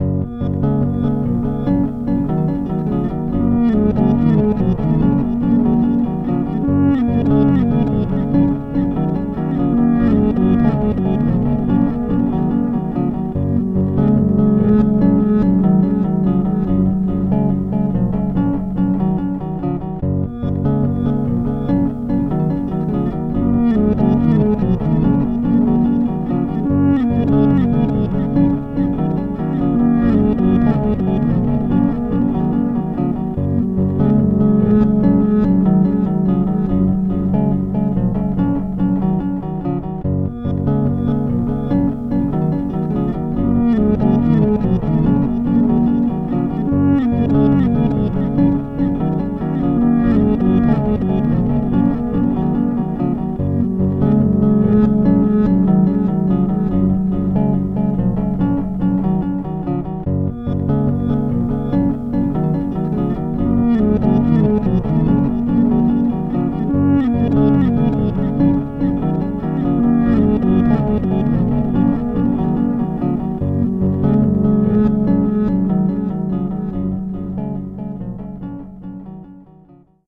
More fun with compressed minimalism.  Recorded with a Fender Jazz bass and Hazarai unit.